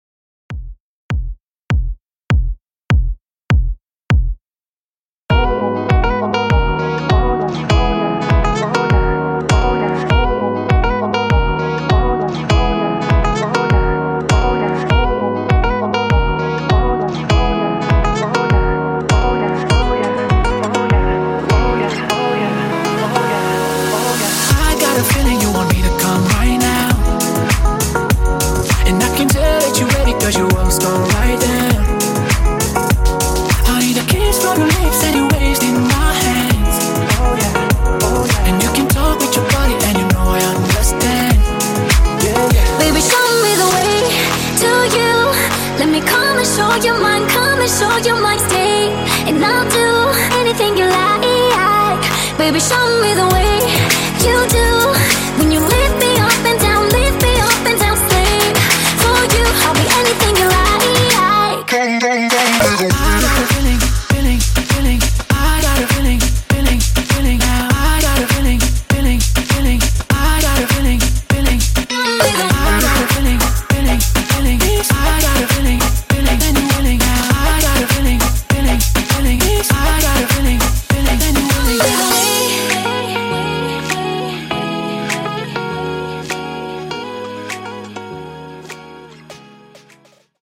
Extended Edit)Date Added